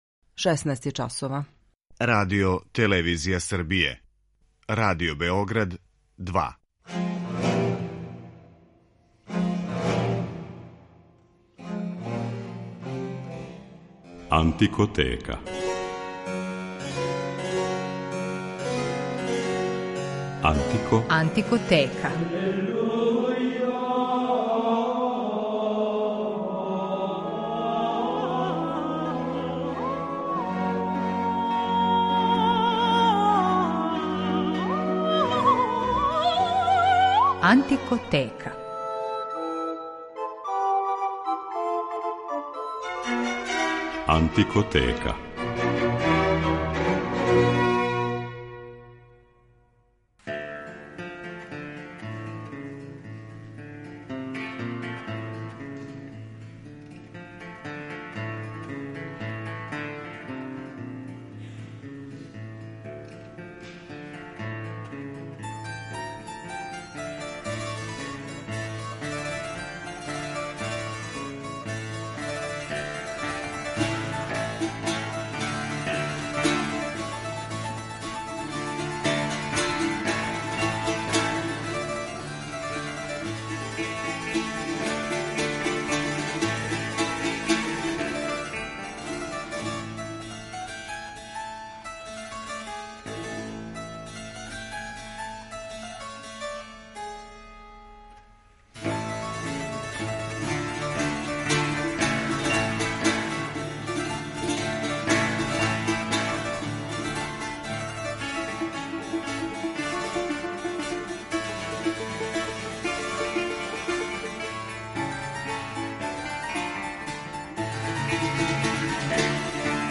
Шпанска музика барока богата је плесним ритмовима који су били извор инспирације многим композиторима да их уметнички обликују.